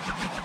archer_army_attack.ogg